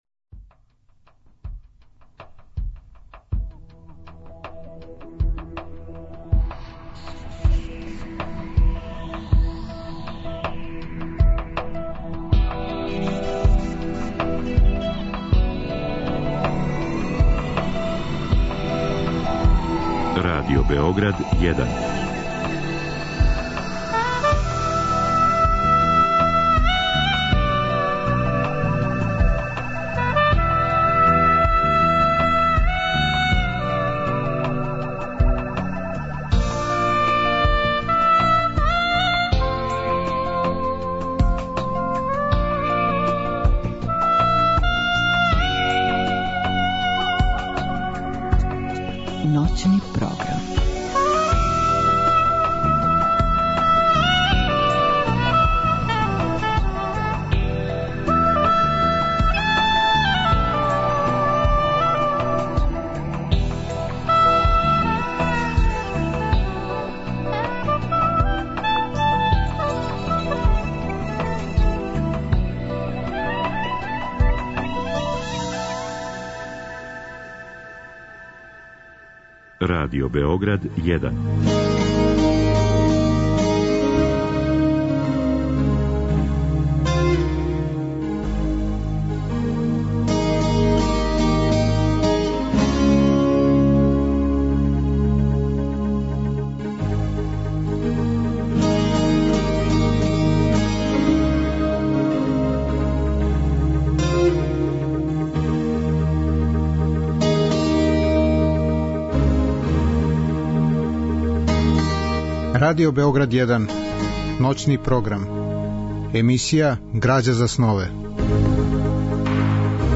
Биће речи о сновима, о тумачењу слика из наших снова и о вези сна и књижевног стварања. У другом делу емисије, од два до четири часа ујутро, слушаћемо радио-драматизације прича Едгара Алана Поа.
Радио-драме су реализоване у продукцији Драмског програма Радио Београда.